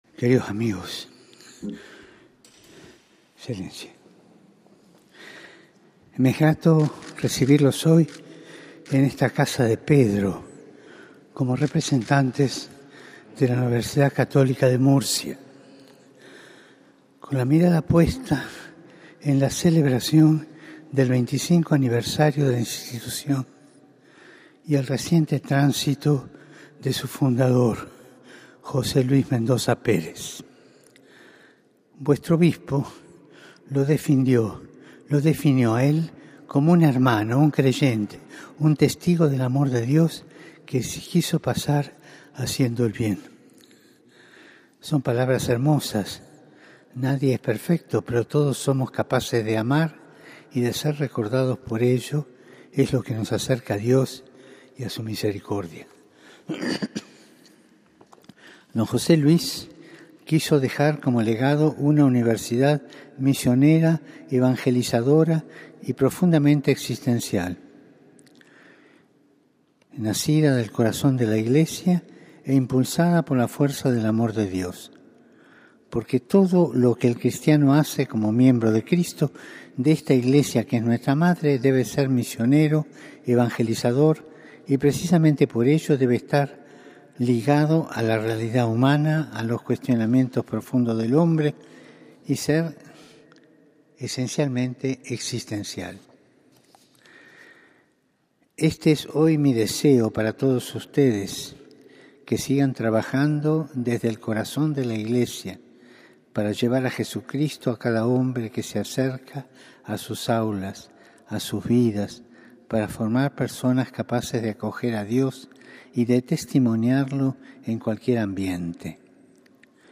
Audiencia del Papa Francisco a representantes de la